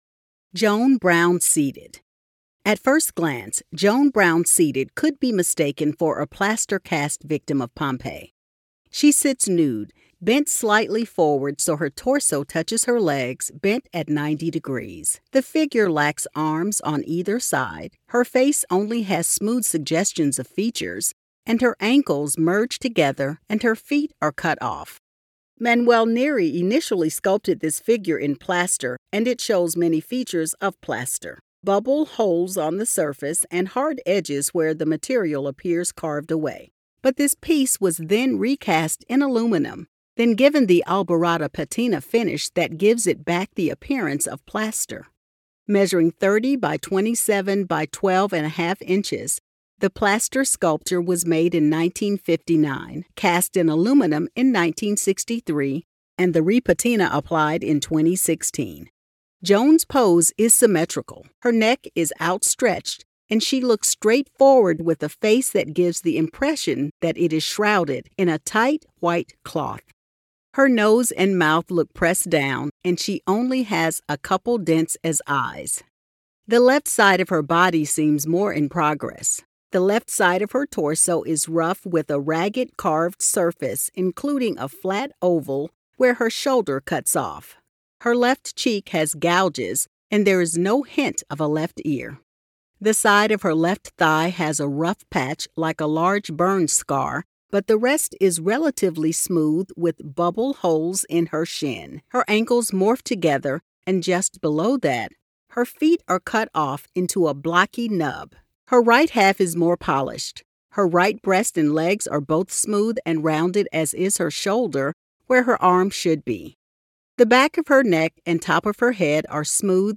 Audio Description (02:13)